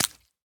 Minecraft Version Minecraft Version snapshot Latest Release | Latest Snapshot snapshot / assets / minecraft / sounds / entity / fish / flop2.ogg Compare With Compare With Latest Release | Latest Snapshot
flop2.ogg